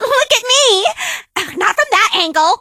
emz_lead_vo_02.ogg